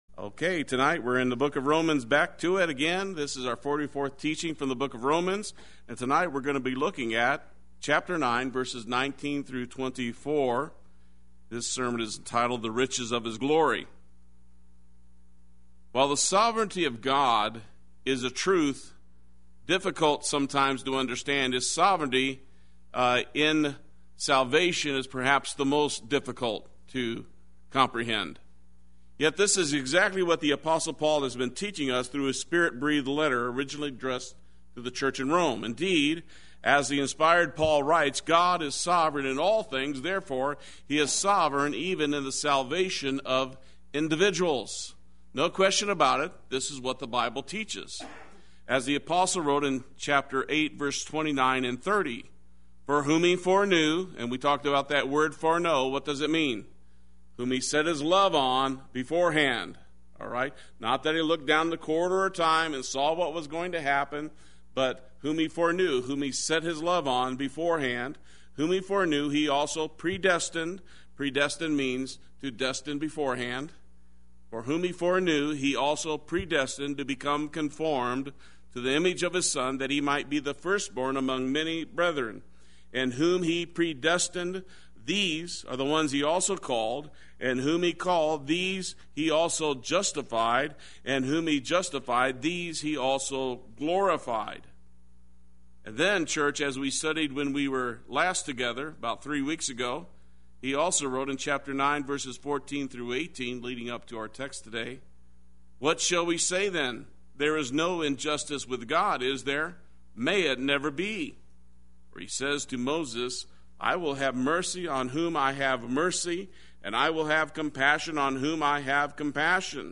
Play Sermon Get HCF Teaching Automatically.
The Riches of His Glory Wednesday Worship